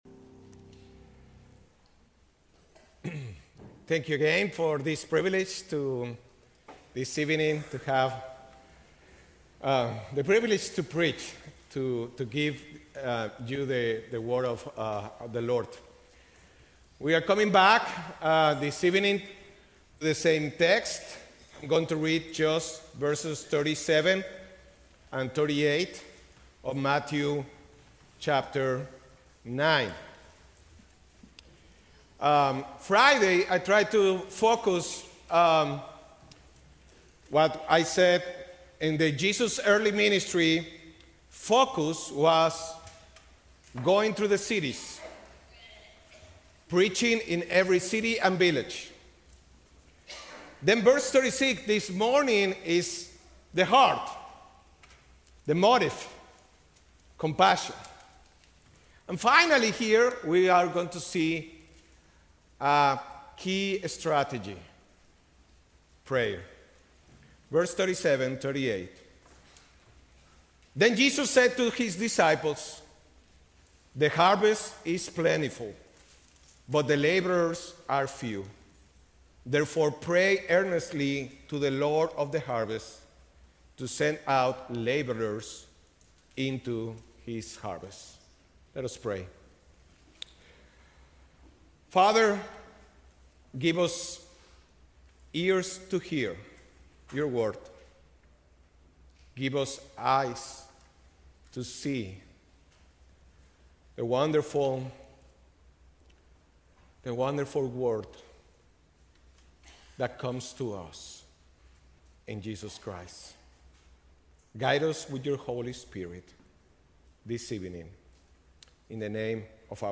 A sermon from the series "2026 Missions Conference."